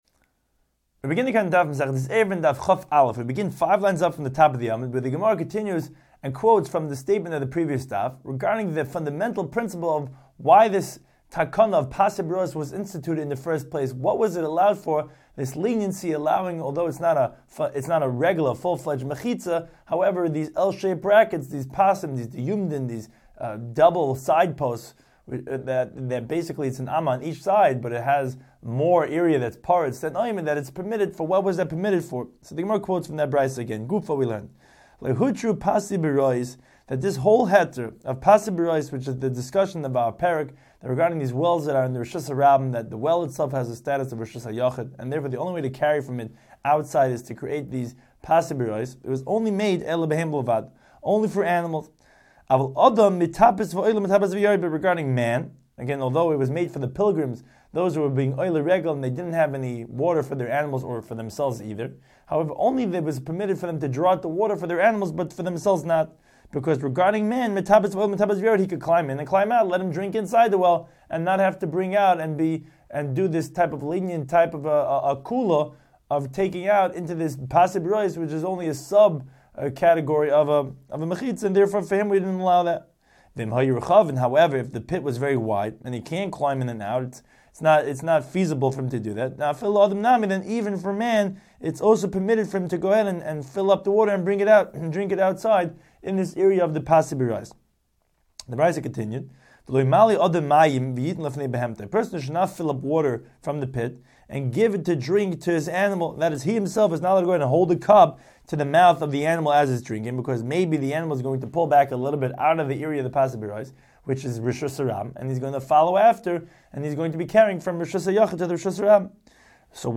Daf Hachaim Shiur for Eruvin 21